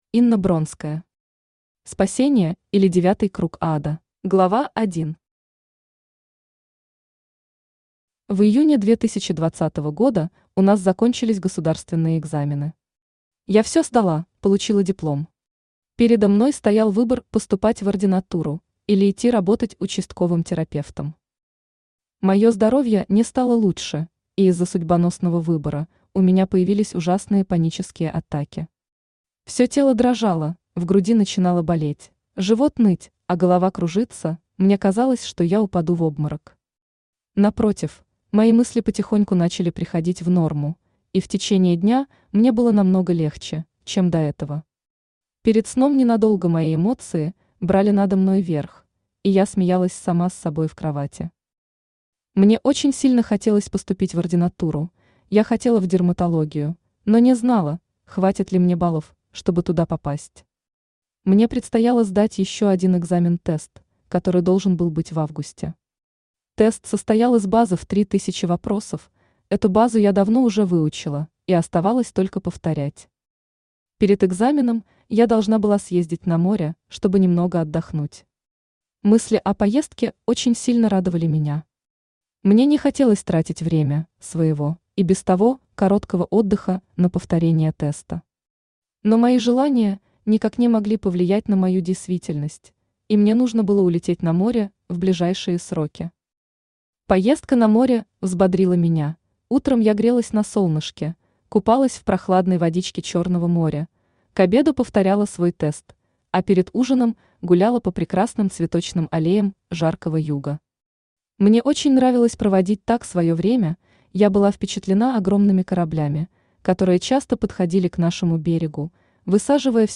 Аудиокнига Спасение, или Девятый круг ада | Библиотека аудиокниг
Aудиокнига Спасение, или Девятый круг ада Автор Инна Дмитриевна Бронская Читает аудиокнигу Авточтец ЛитРес.